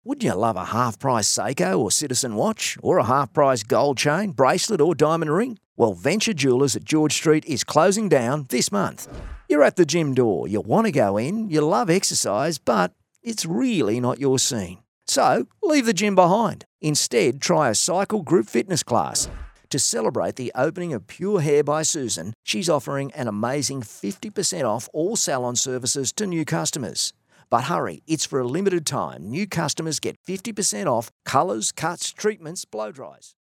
But pretty much specialises in the laid back Aussie style, relaxed , real and believable!
• Retail Friendly
• Natural Aussie Bloke